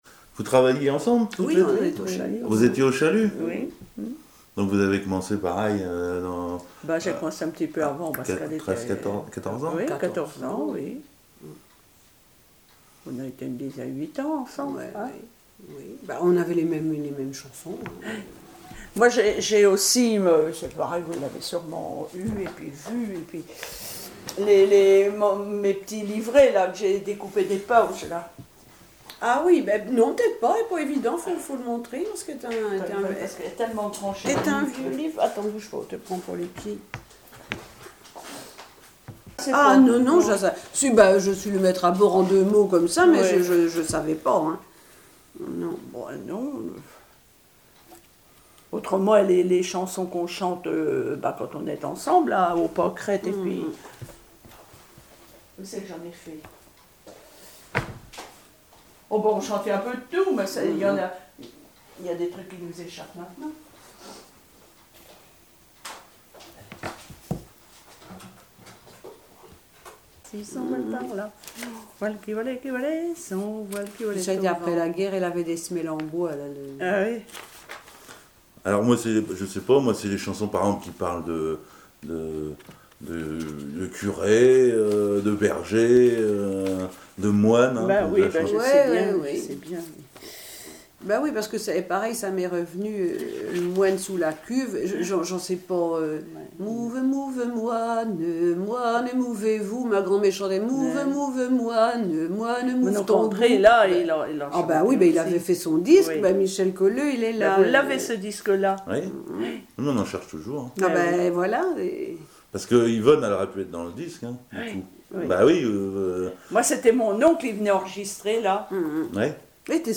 Chansons et commentaires
Témoignage